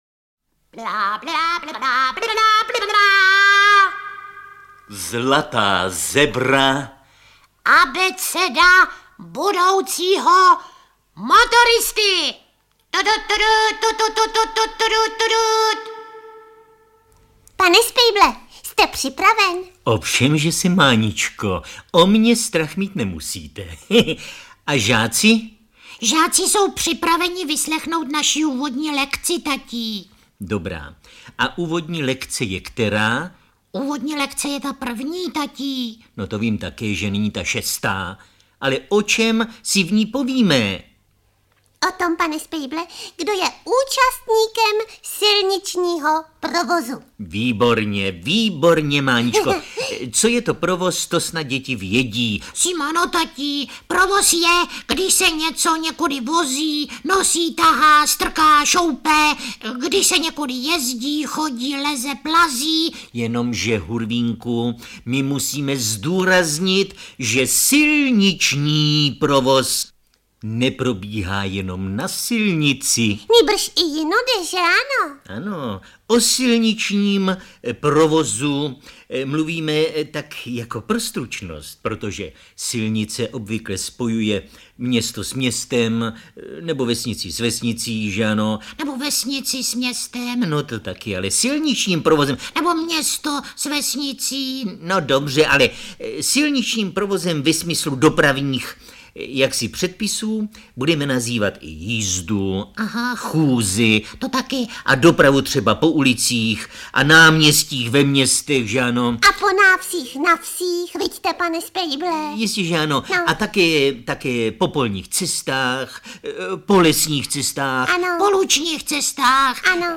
Délka: 2 h 40 min Interpret: Jiří Lábus Vydavatel: Tympanum Vydáno: 2023 Jazyk: český Typ souboru: MP3 Velikost: 150 MB